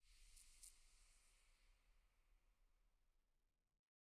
eyeblossom_close3.ogg